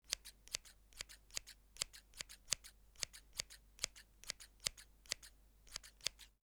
PIANETA GRATIS - Audio Suonerie - Casa (Domestici) - Pagina 37
scissors-1.wav